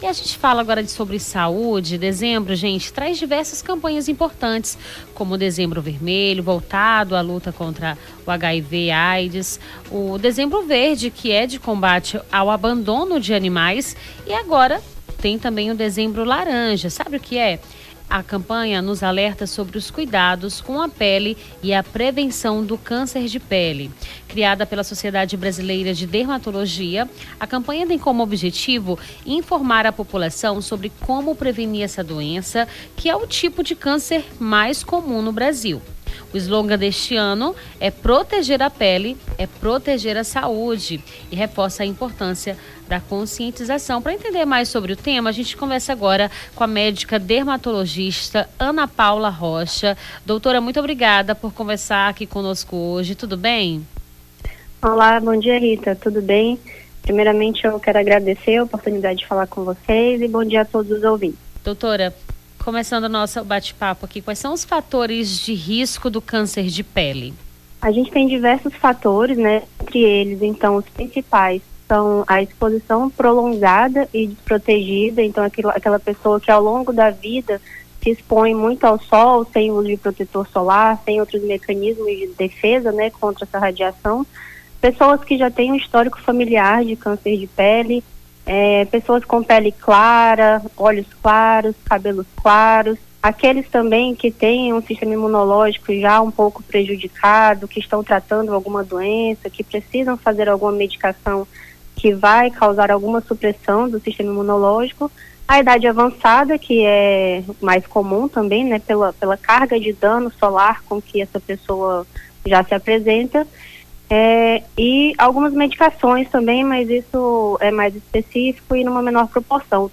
Nome do Artista - CENSURA - ENTREVISTA DEZEMBRO LARANJA (11-12-24).mp3